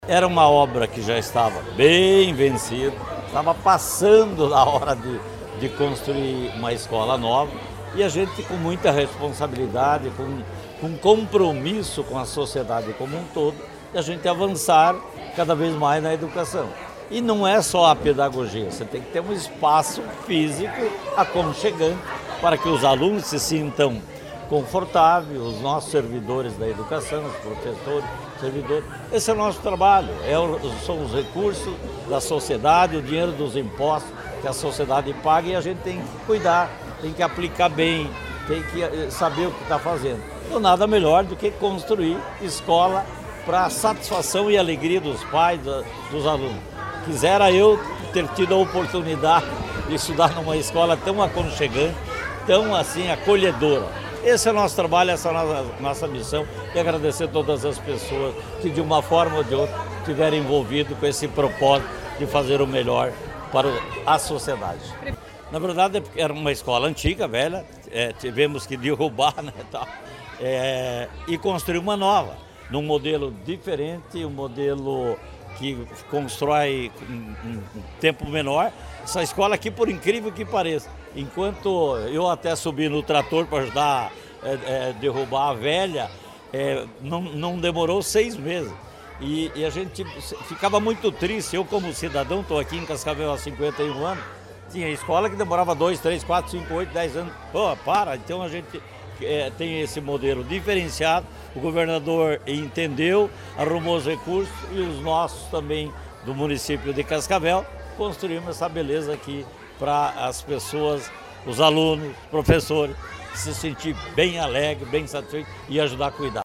Sonora do prefeito de Cascavel, Renato Silva, sobre a inauguração da escola da cidade